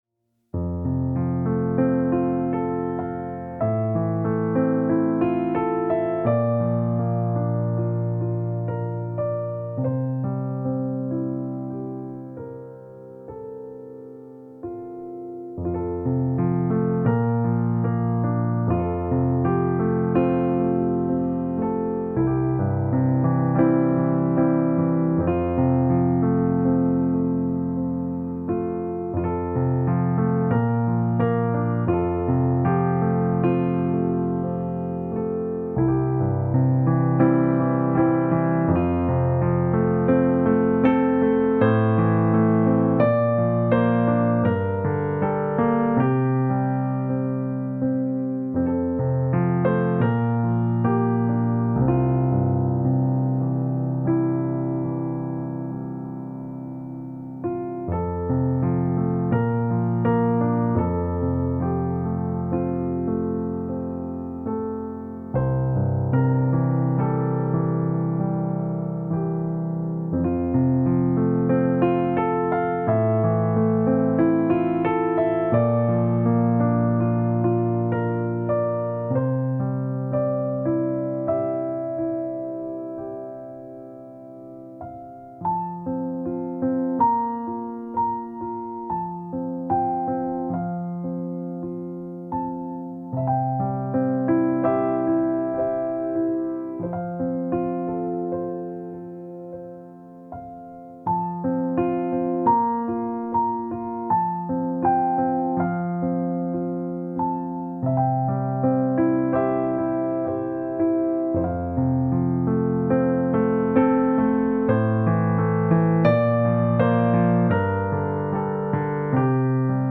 Piano Solo
Voicing/Instrumentation: Piano Solo We also have other 1 arrangement of " My Jesus, I Love Thee ".